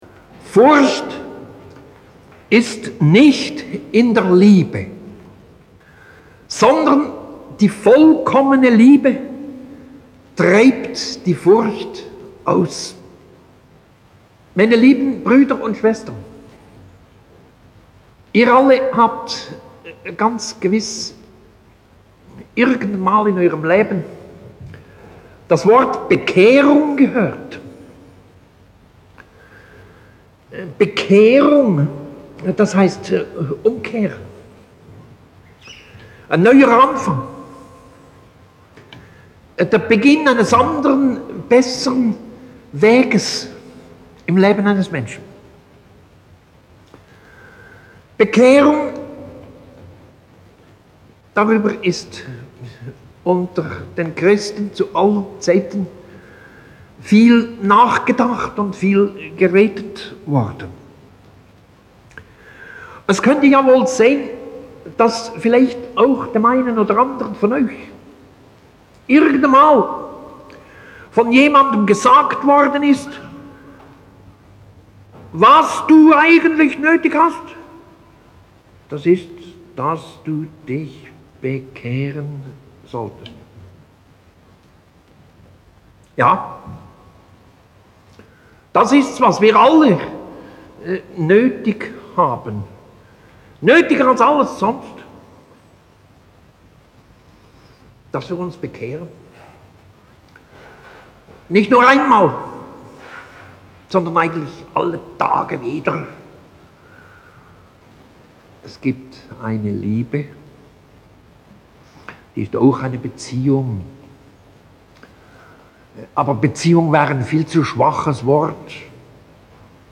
Ausschnitte aus Karl Barth, Furcht ist nicht in der Liebe. Predigt über 1. Joh. 4, 18, gehalten in der Strafanstalt in Basel am 6. August 1961.